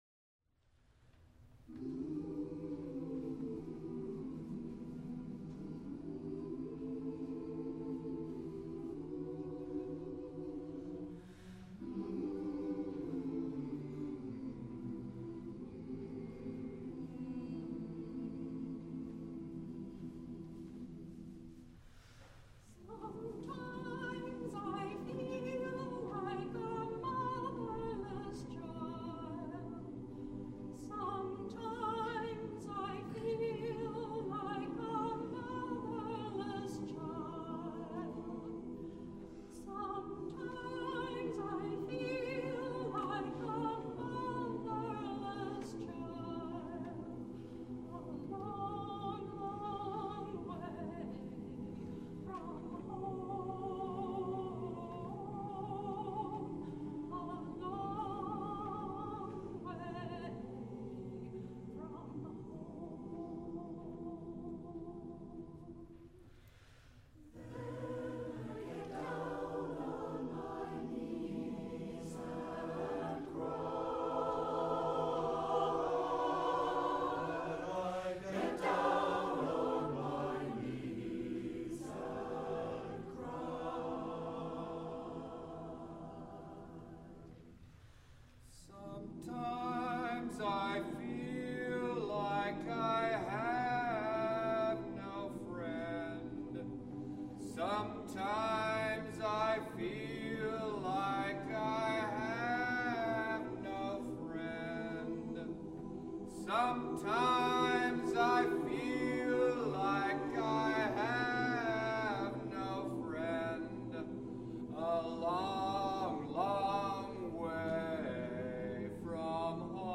Spritual - arranged for SATB a cappella with multiple solos
The solos can be improvised around the melody.